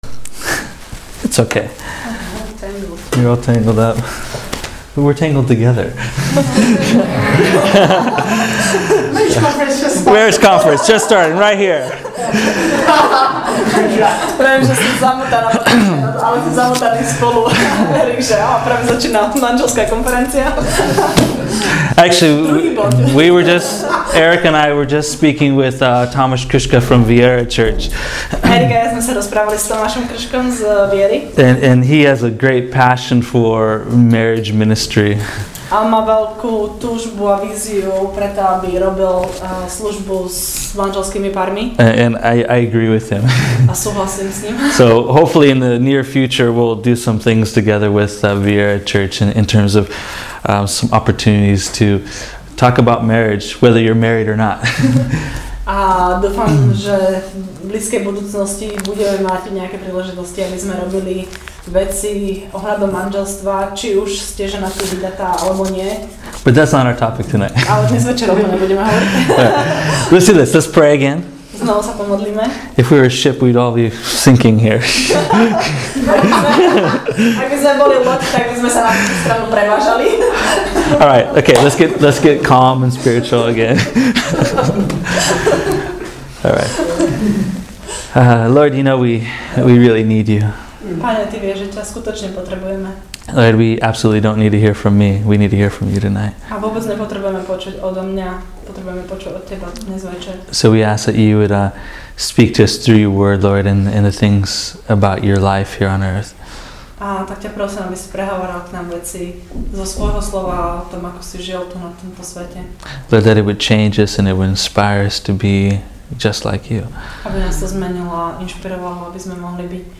Sorry this week’s audio recording was a digital disaster.